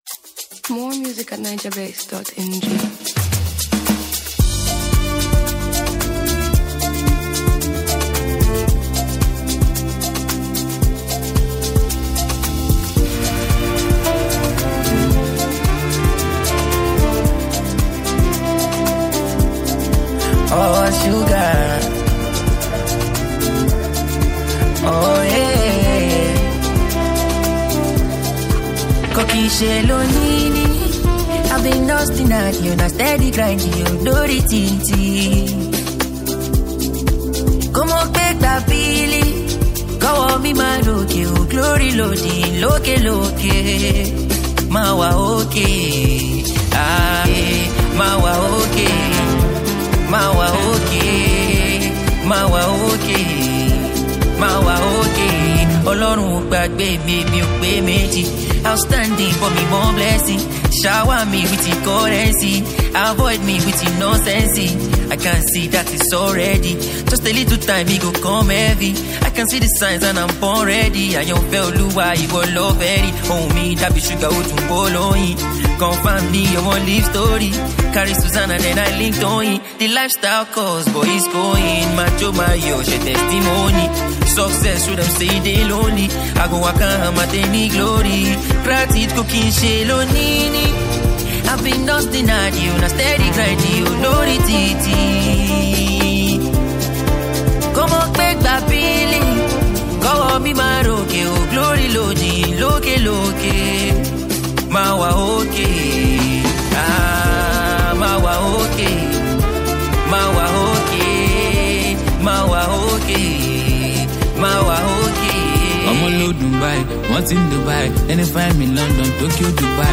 Afrobeats track